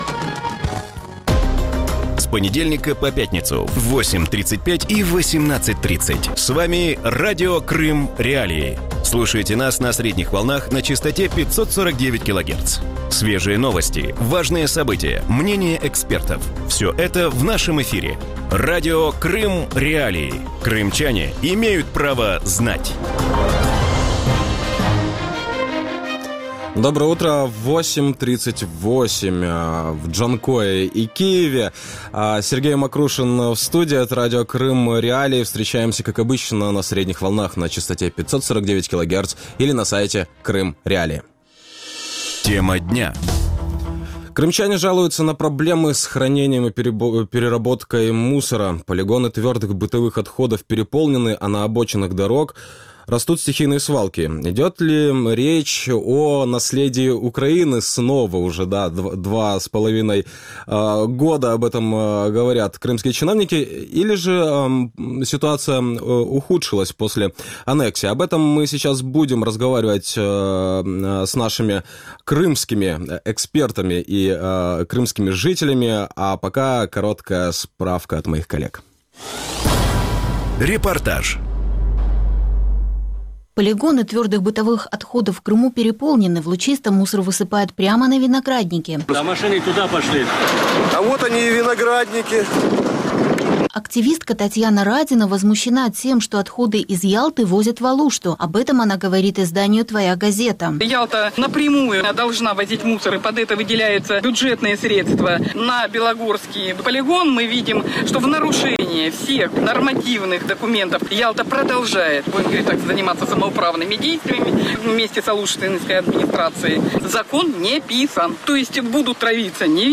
Вранці в ефірі Радіо Крим.Реалії говорять про про проблеми зі зберіганням і переробкою сміття в Криму. Полігони твердих побутових відходів переповнені, а на узбіччях доріг ростуть стихійні звалища. Чи йдеться про спадщину України, або ж після анексії ситуація тільки погіршилася?